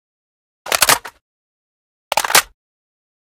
reload.ogg